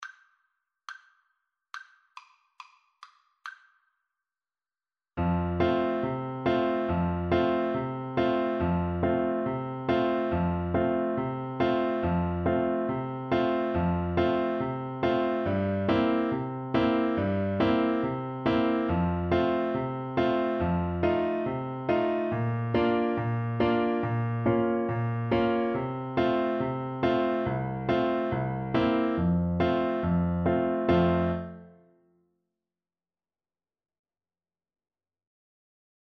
American gospel hymn.
Vivo =140